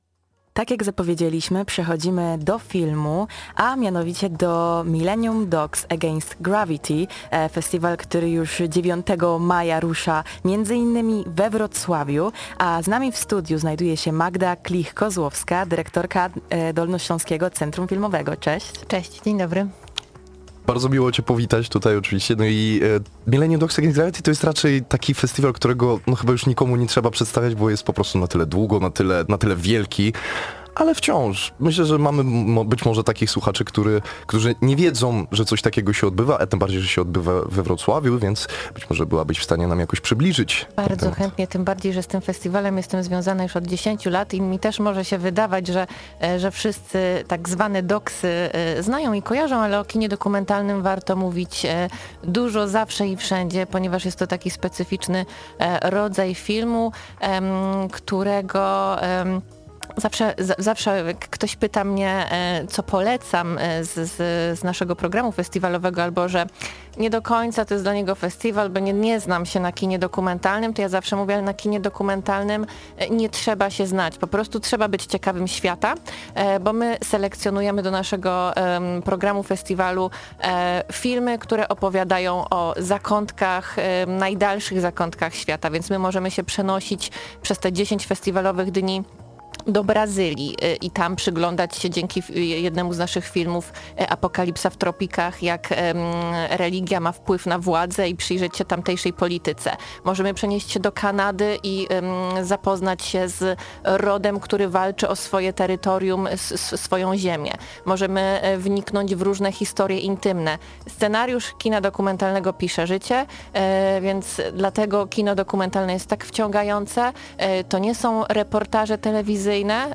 mdag-rozmowa.mp3